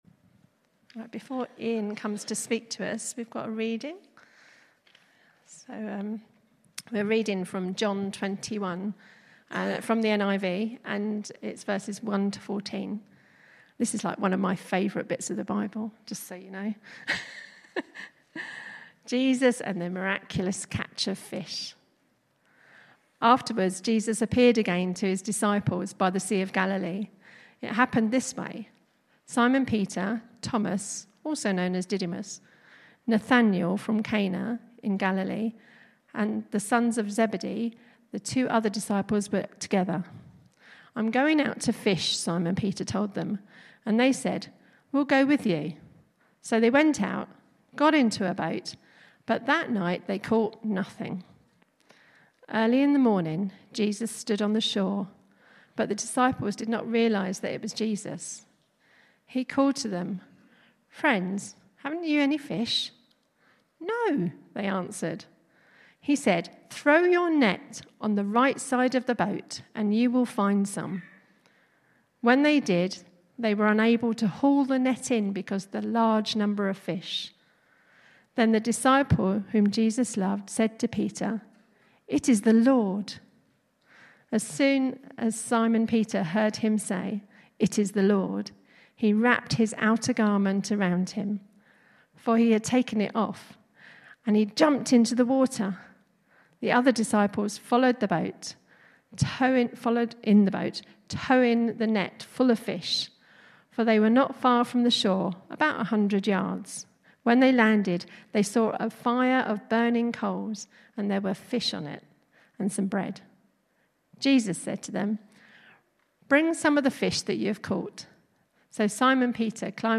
Morning talk